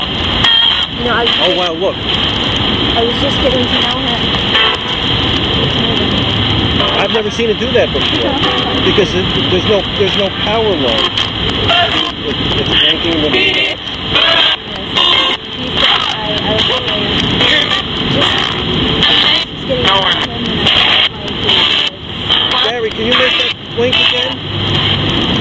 Between 13-15 seconds there is what sounds like a 3 word sentence said, but I cannot make it out.   At 16-17 seconds you can clearly hear a male voice say "Look.....hear me!"
At 20 seconds a female voice says "no one".